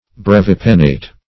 Search Result for " brevipennate" : The Collaborative International Dictionary of English v.0.48: Brevipennate \Brev`i*pen"nate\, a. [L. brevis short + E. pennate.]